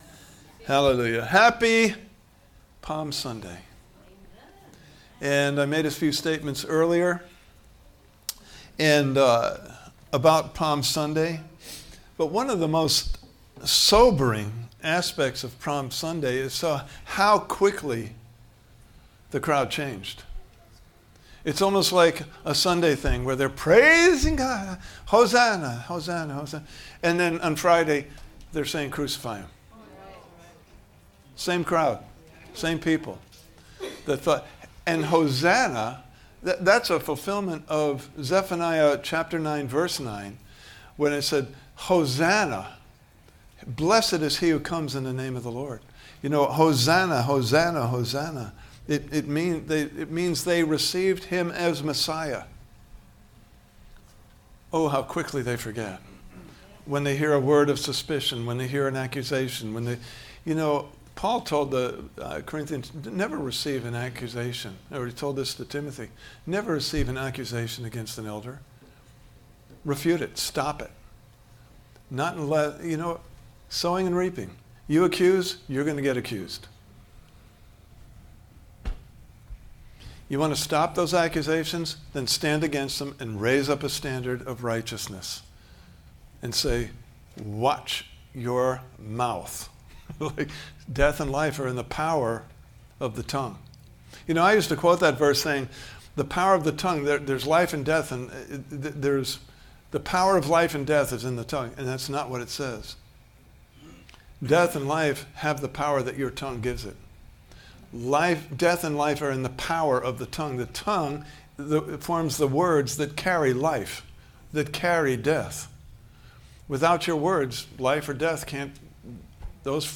Series: Are You Hearing or Listening? Service Type: Sunday Morning Service « Your Faith Has Made You Well (Video) Part 3: Two Ears – One Mouth!